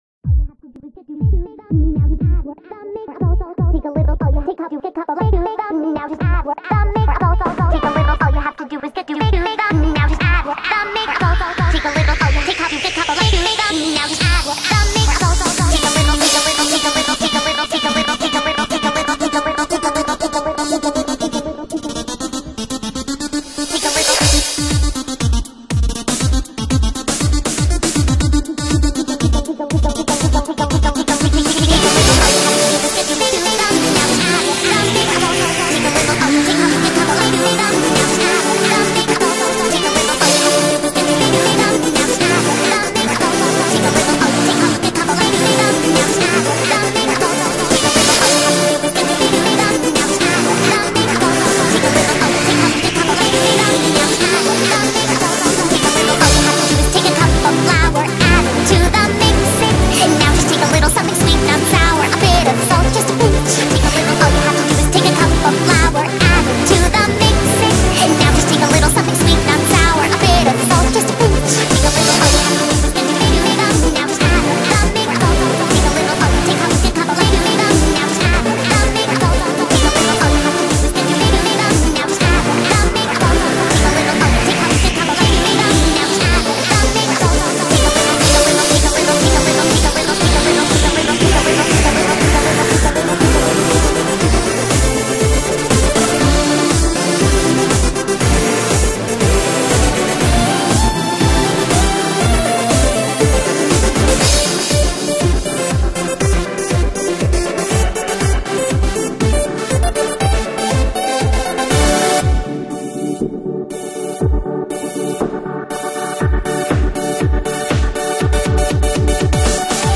I guess this is some sort of cupcakes remix.